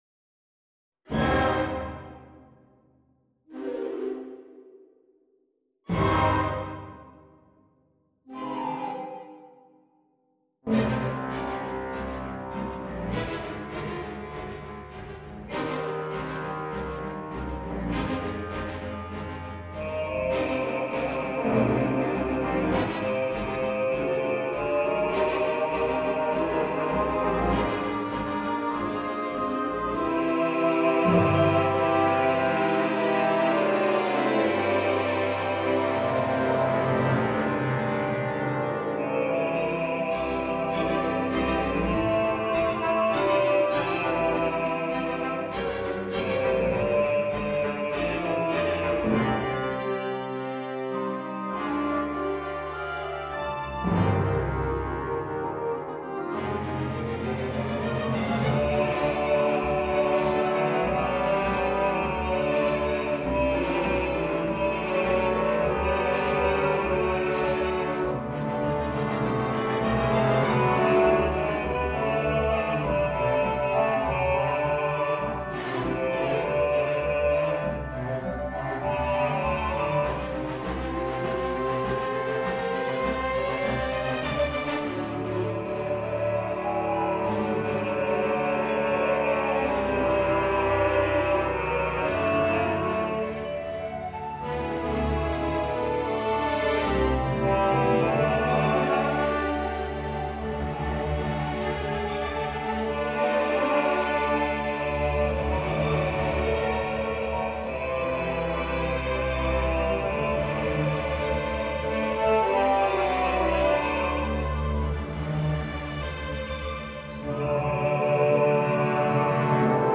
20′ Solo Baritone, Small Orchestra
rock-influenced